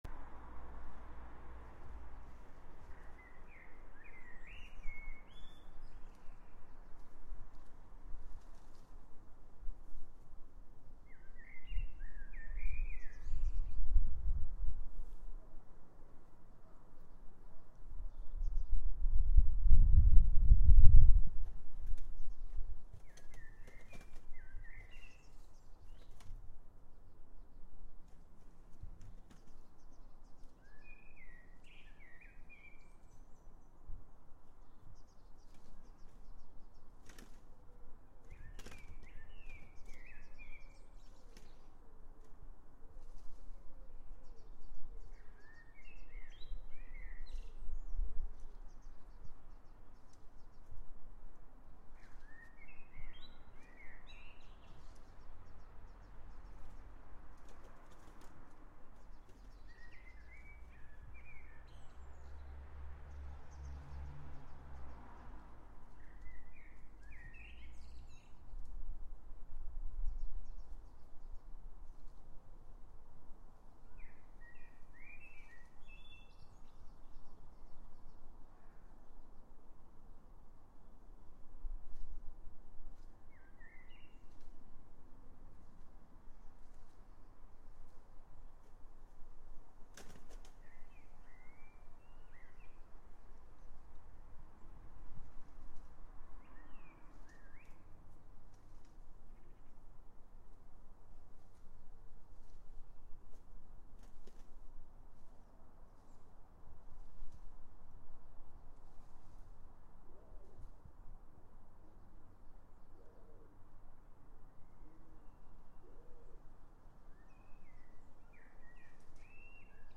Sunday afternoon birds 8. Includes wing flaps near start and collared dove, I think.